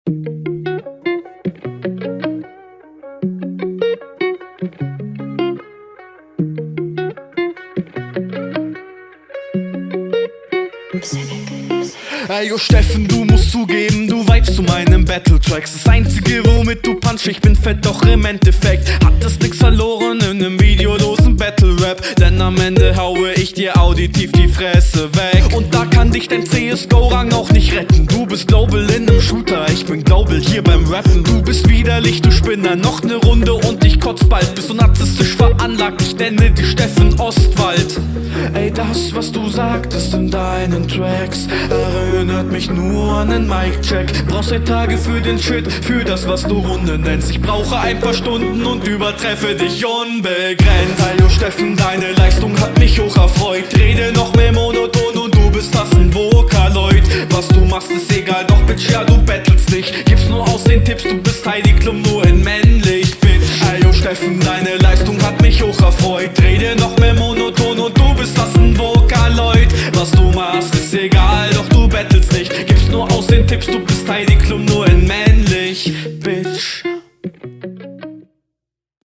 Bissl sehr poppig.